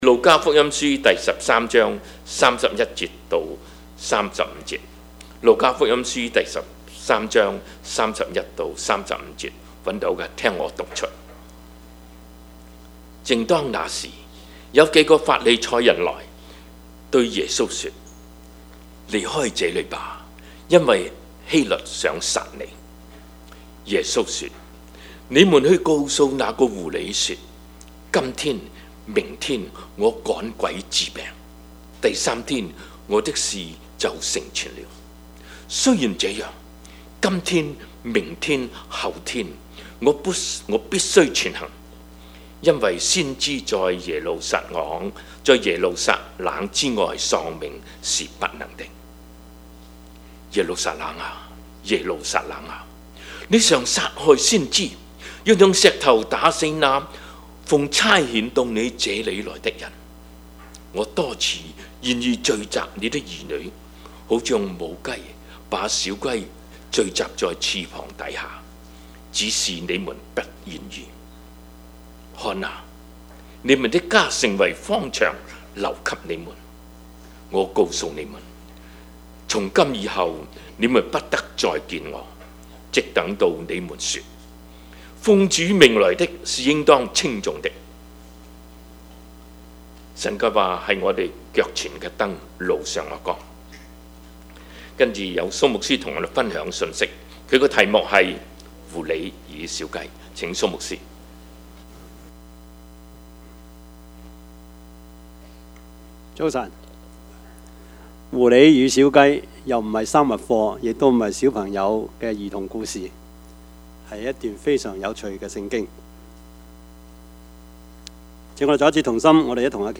Service Type: 主日崇拜
Topics: 主日證道 « 大與小、寬與窄、前與後 凡事謝恩 »